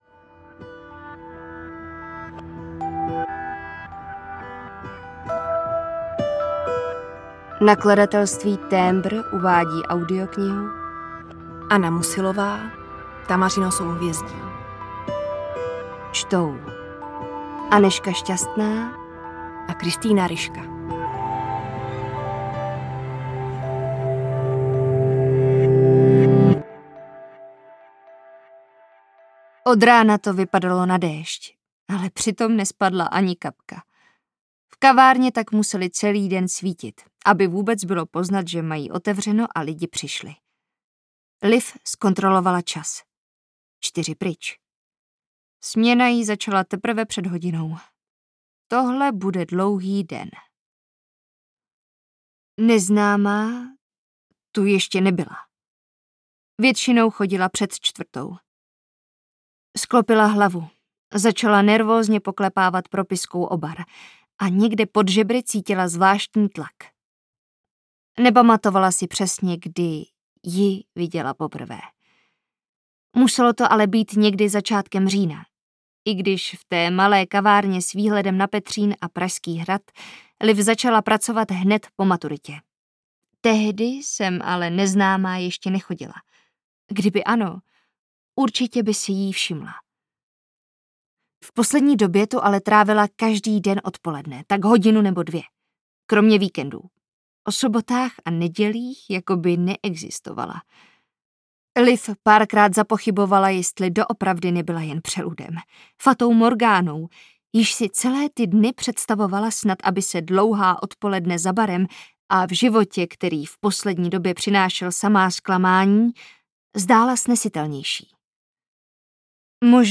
Tamařino souhvězdí audiokniha
Ukázka z knihy